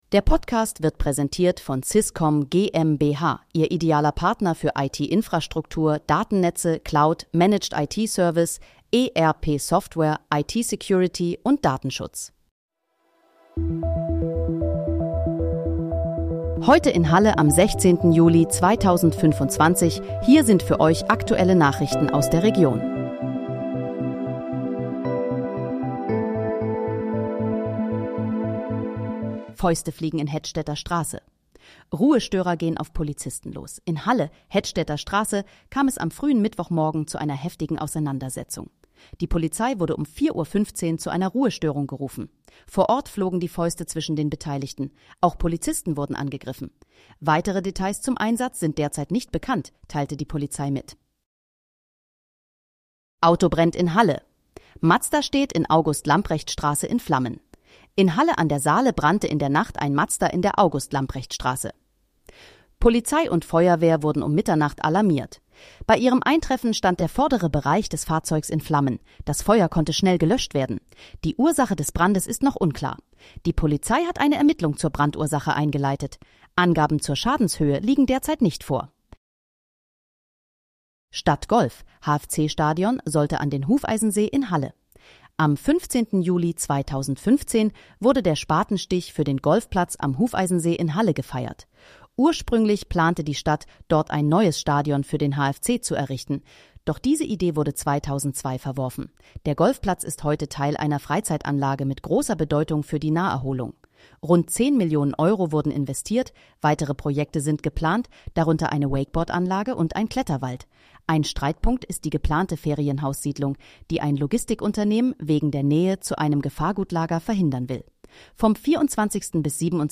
Heute in, Halle: Aktuelle Nachrichten vom 16.07.2025, erstellt mit KI-Unterstützung
Nachrichten